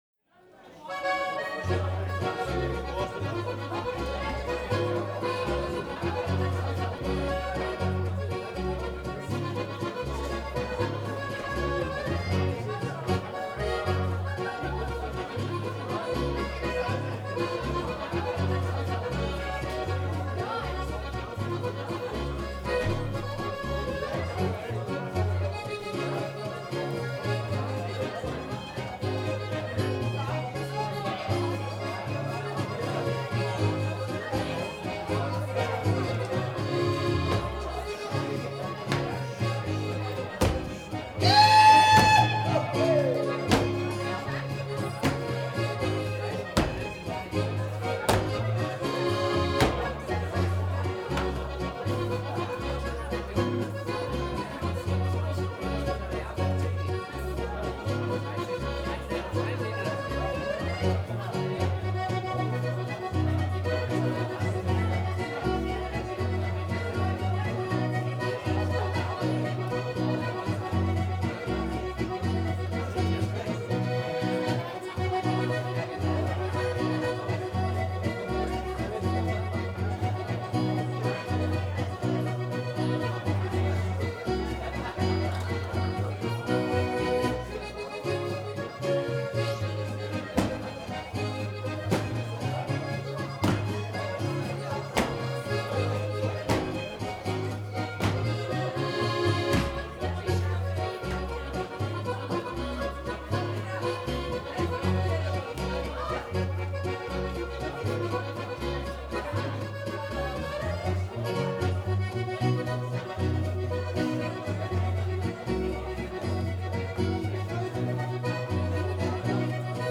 Jodler, Jodler-Lied, Gstanzl und Tanz
Folk & traditional music